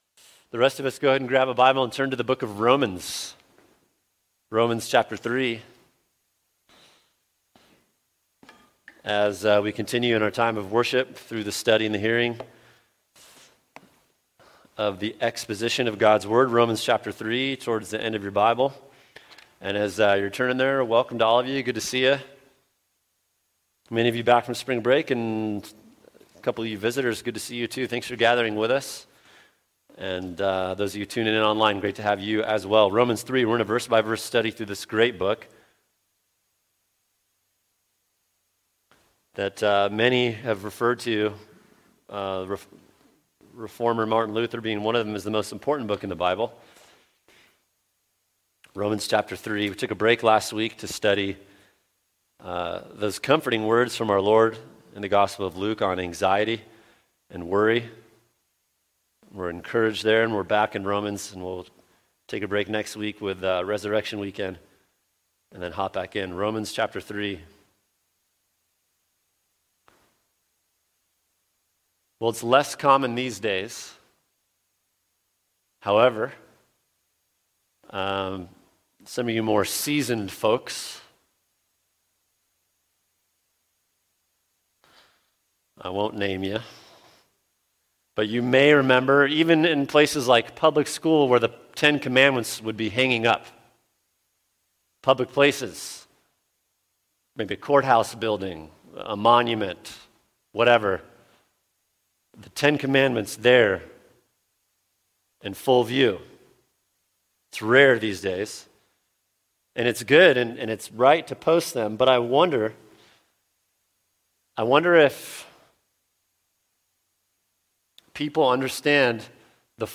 [sermon] Romans 3:19-20 Every Mouth Closed | Cornerstone Church - Jackson Hole